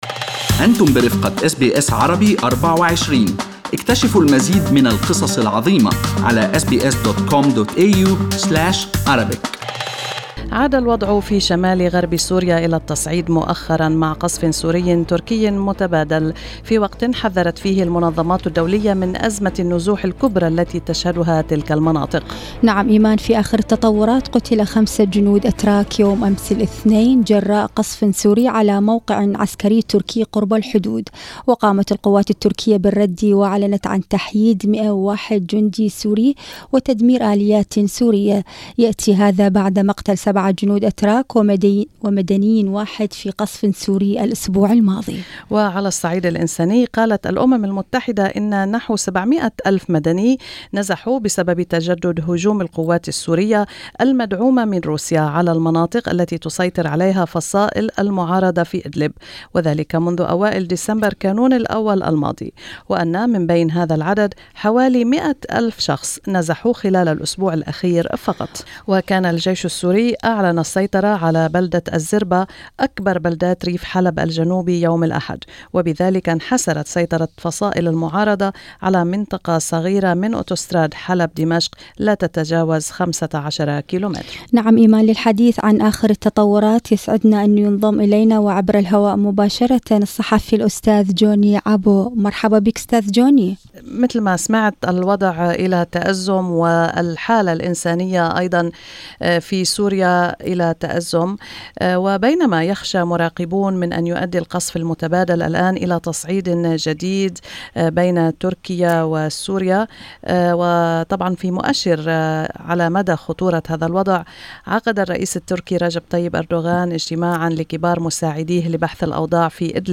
ويقول الصحافي